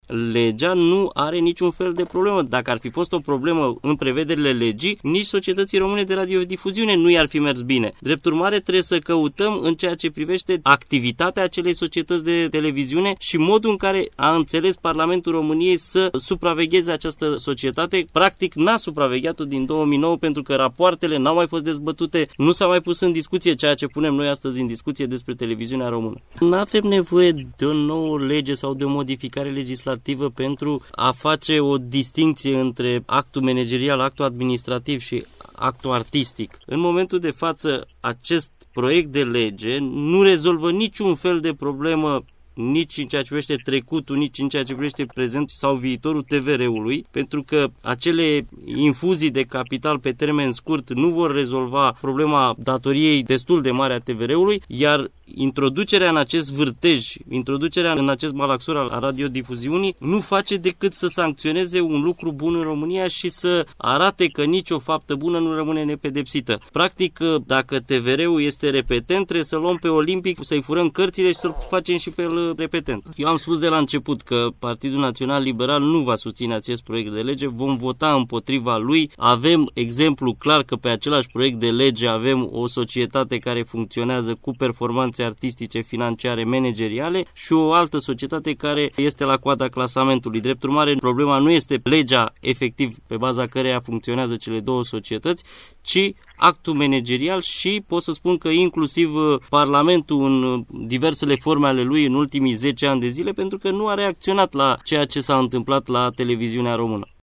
Proiectul de modificare a Legii 41/1994 – care reglementează organizarea și funcţionarea Societăţilor Române de Radio şi de Televiziune – a fost subiectul dezbaterii în cadrul emisiunii ”Pulsul zilei” de marți, 10 martie 2016, difuzată la Radio România Oltenia Craiova.
Senatorul PNL Mărinică Dincă a susținut nevoia redresării televiziunii publice, fără a afecta activitatea Societății Române de Radiodifuziune, iar, în opinia sa, propunerea legislativă inițiată și semnată de 67 de parlamentari PSD nu soluționează crize din TVR: